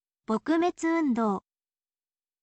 bokumetsu undou